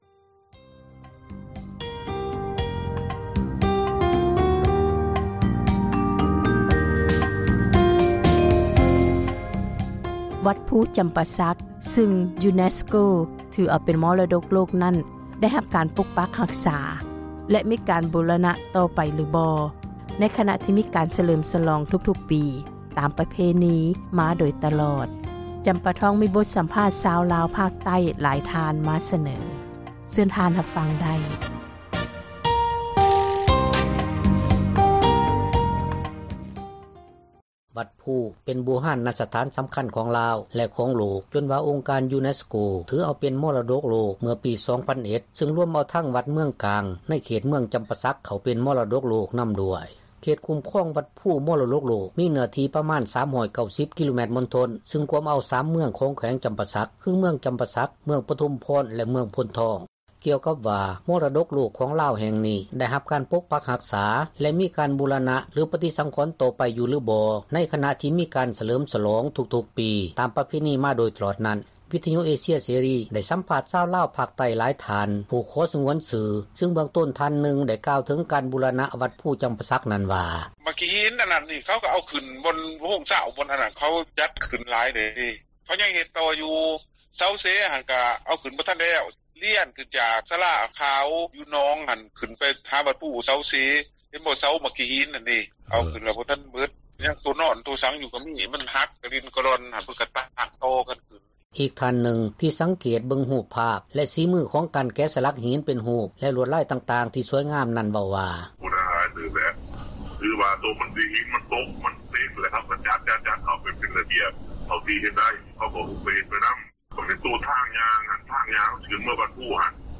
ກ່ຽວກັບວ່າ ມໍຣະດົກໂລກ ຂອງລາວແຫ່ງນີ້ ໄດ້ຮັບການປົກປັກຮັກສາ ແລະມີ ການບູຣະນະ ຫລືປະຕິສັງຂອນຕໍ່ໄປຢູ່ບໍຫລືບໍ່ ໃນຂນະທີ່ ມີການສເລີມ ສລອງທຸກໆປີຕາມປະເພນີມາໂດຍຕລອດນັ້ນ ວິທຍຸເອເຊັຍເສຣີ ໄດ້ສຳພາດຊາວລາວພາກໃຕ້ຜູ້ຂໍສງວນຊື່ຫຼາຍທ່ານ ຊຶ່ງເບື້ອງຕົ້ນ ທ່ານນຶ່ງ ໄດ້ກ່າວເຖິງການບູຣະນະວັດພູຈຳປາສັກວ່າ: